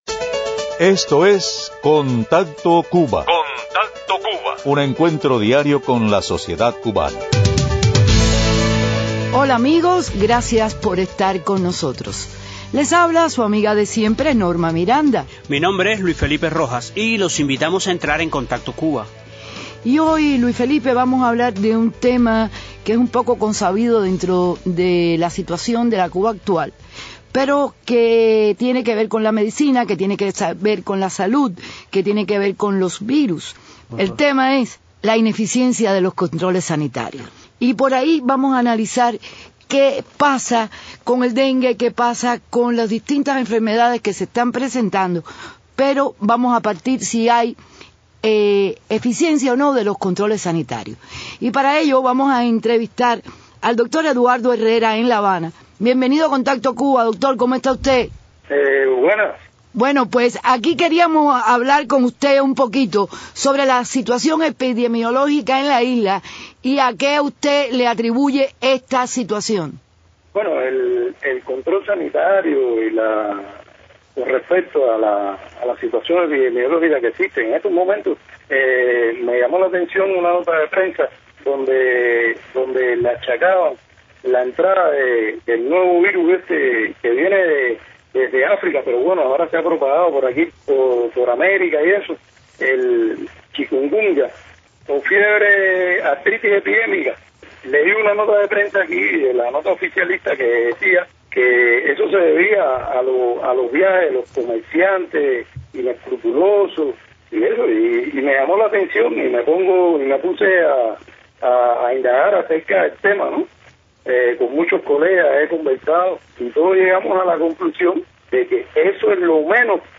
La deficiencia en los controles sanitarios en Cuba, parecen dejar una brecha abierta al paso de epidemias como el dengue y el chkunguya. Los testimoniantes, entre ellos un doctor, ofrecen detalles importantes en Contacto Cuba.